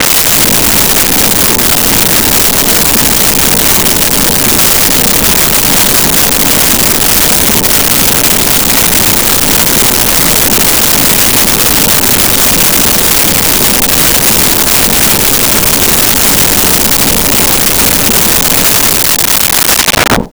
Underwater In Submarine
Underwater in Submarine.wav